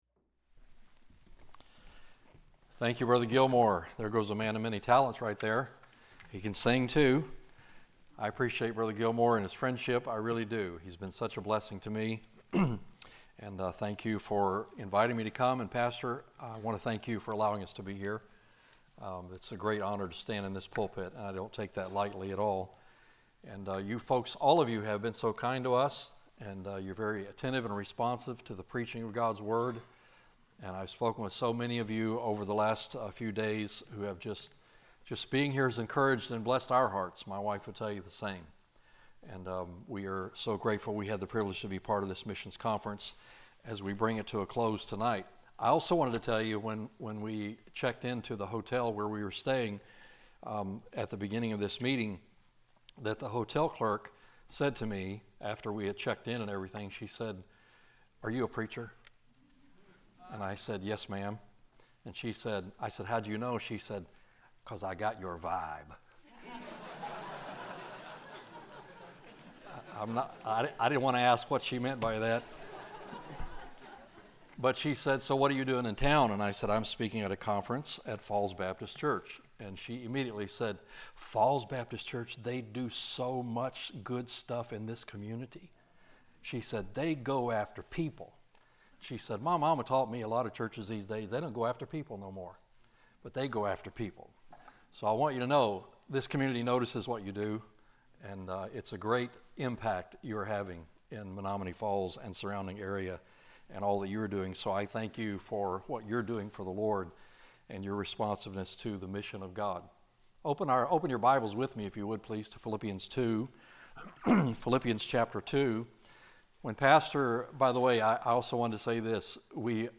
2025 Missions Conference Archives - Falls Baptist Church